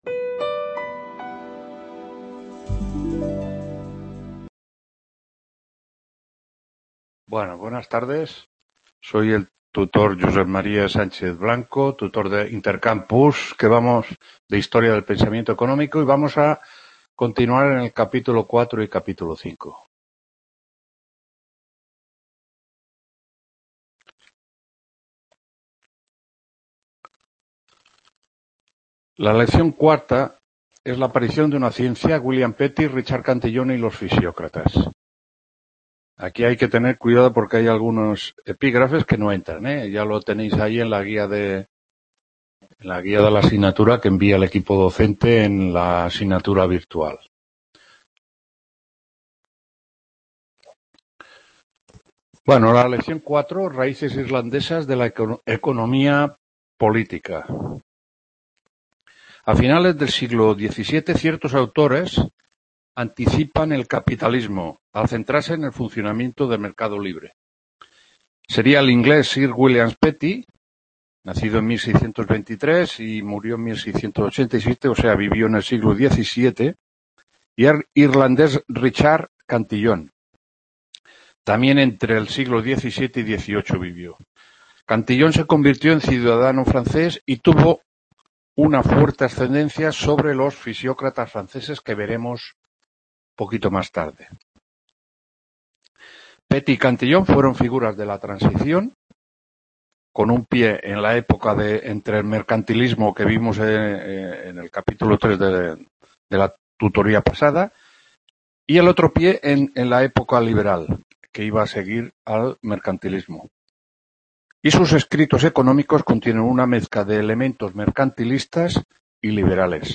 2ª TUTORÍA HISTORIA DEL PENSAMIENTO ECONÓMICO 19-X-19…